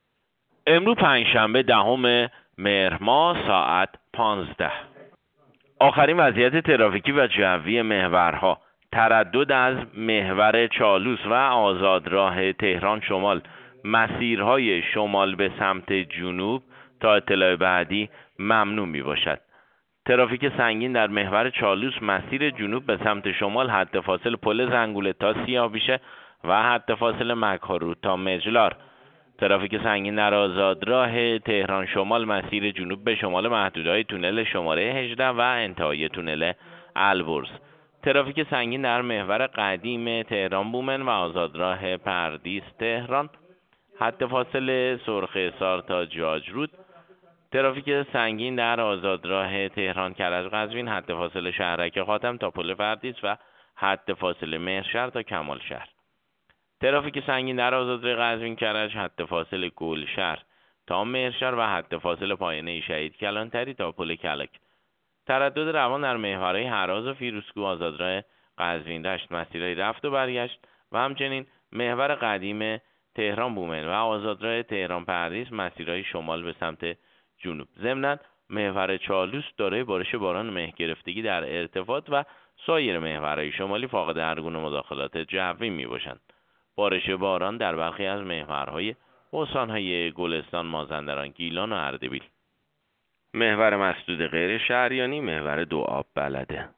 گزارش رادیو اینترنتی از آخرین وضعیت ترافیکی جاده‌ها ساعت ۱۵ دهم مهر؛